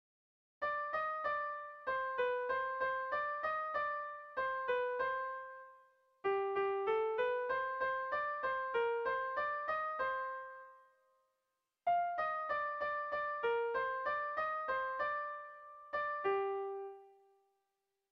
Dantzakoa
Seiko txikia (hg) / Hiru puntuko txikia (ip)
ABD